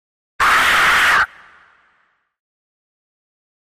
Alien Screech Scream 2 - Monster Dinosaur